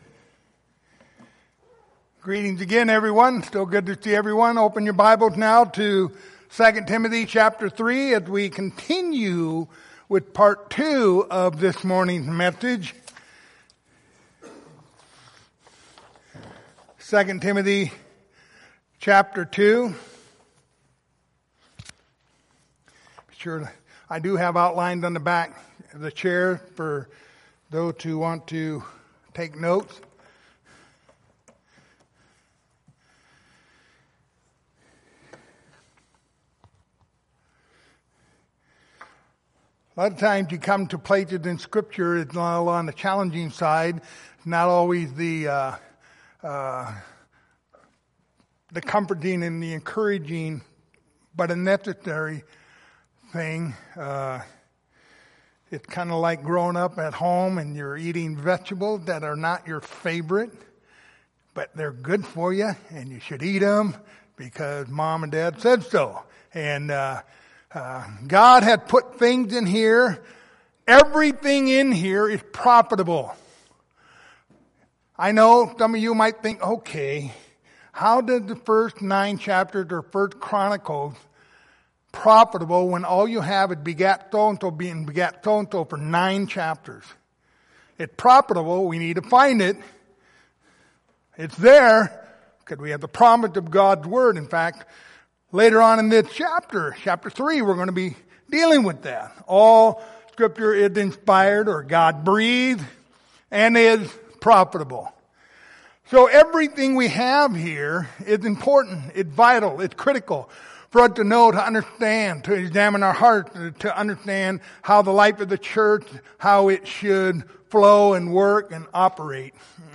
2 Timothy 3:1-5 Service Type: Sunday Evening Topics